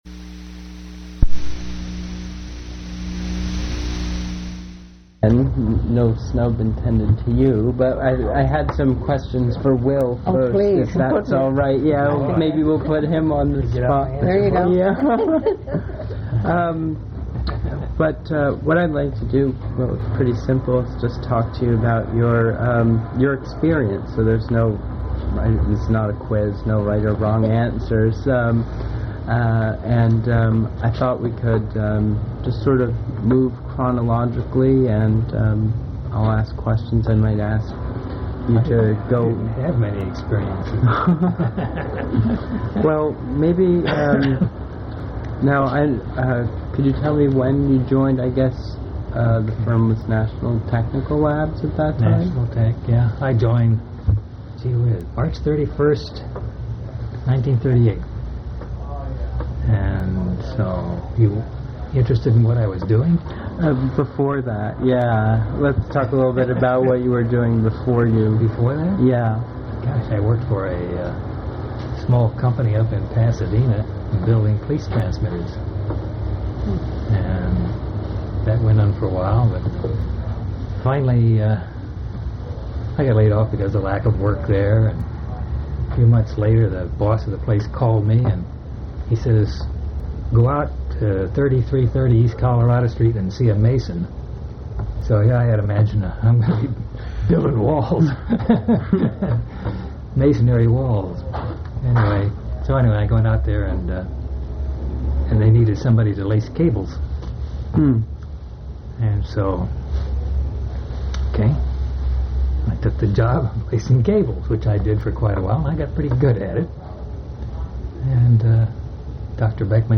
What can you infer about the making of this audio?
Place of interview California--Fullerton